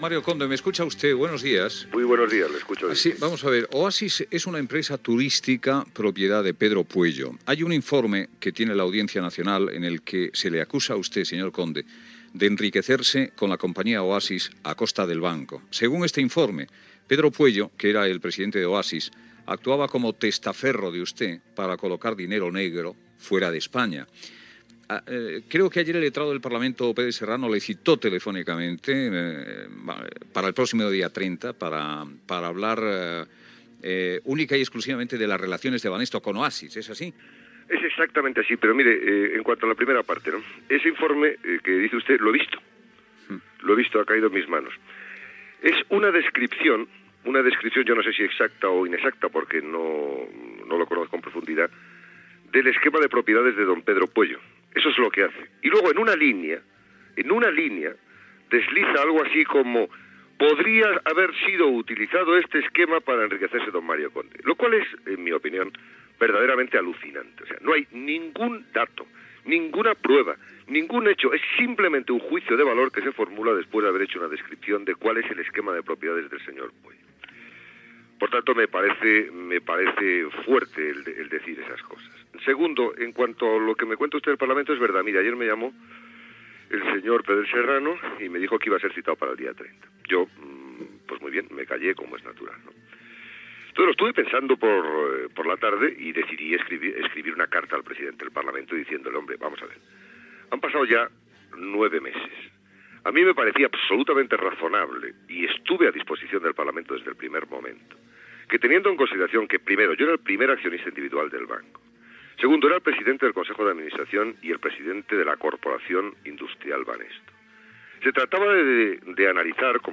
Fragment d'una entrevista al banquer Mario Conde sobre la companyia Oasis i el seu suposat enriquiment. Luis del Olmo li proposa ser tertulià del programa. Una oient truca indignada per la proposta.
Info-entreteniment